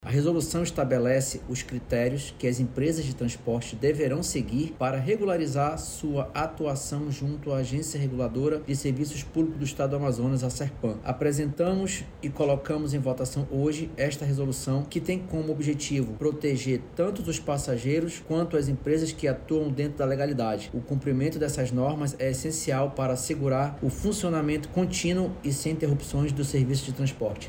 As empresas de transporte vão ter que seguir critérios para regularizar sua atuação junto à Agência Reguladora de Serviços Públicos do Estado do Amazonas – Arsepam, destaca o diretor-presidente da Agência, Ricardo Lasmar.
SONORA-1-NOVOS-CRITERIOS-TRANSPORTE-.mp3